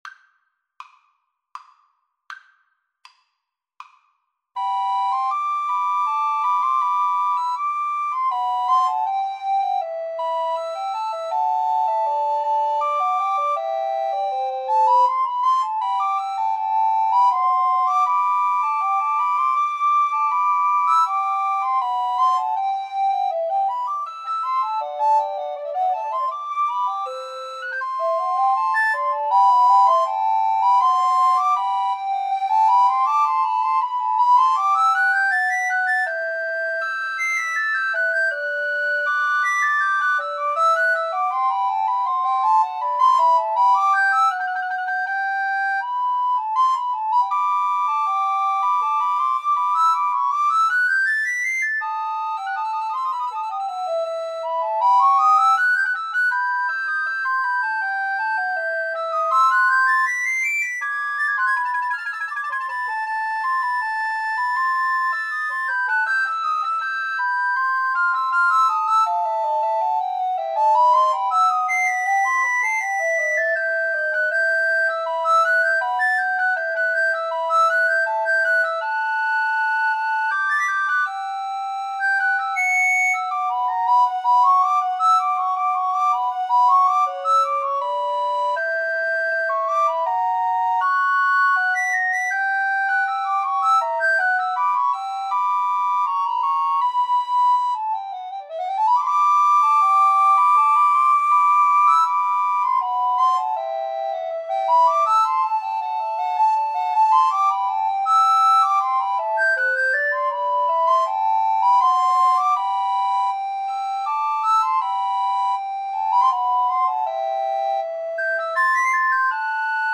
3/4 (View more 3/4 Music)
Andante
Classical (View more Classical Recorder Trio Music)